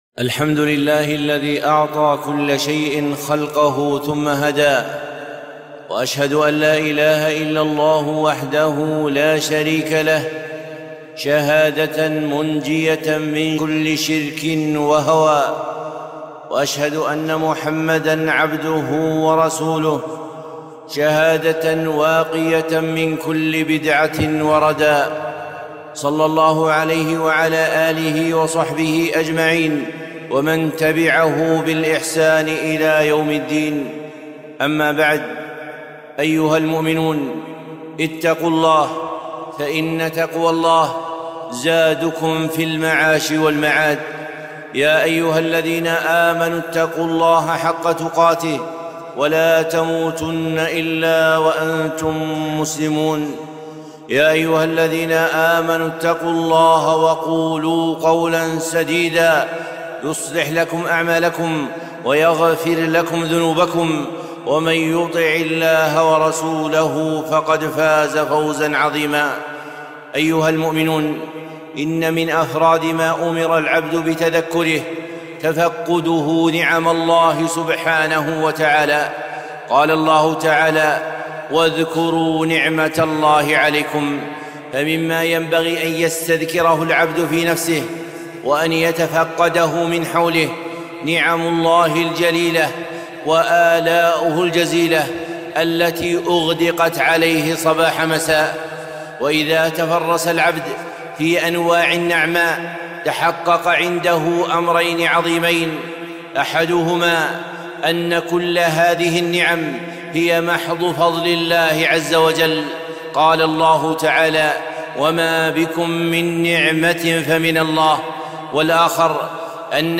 خطبة - النعم الخمس